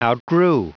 Prononciation du mot outgrew en anglais (fichier audio)